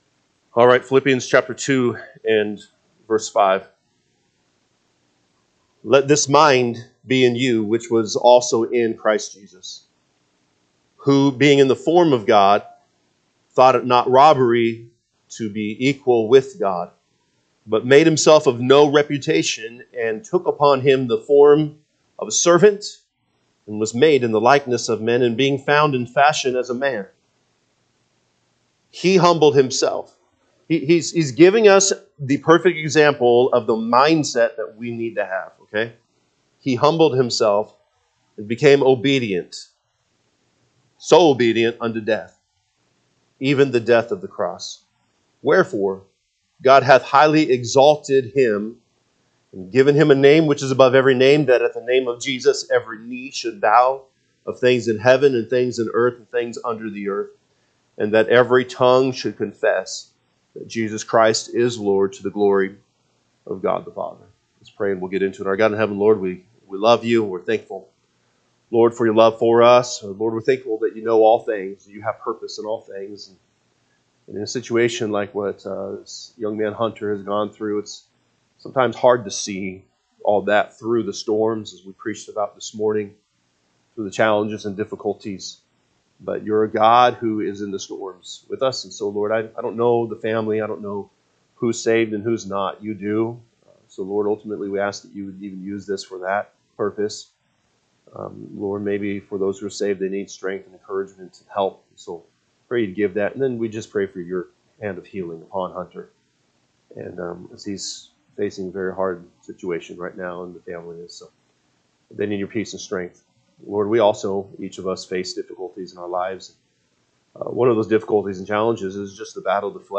March 2, 2025 pm Service Philippians 2:5-11 (KJB) 5 Let this mind be in you, which was also in Christ Jesus: 6 Who, being in the form of God, thought it not robbery to be equal with God: …
Sunday PM Message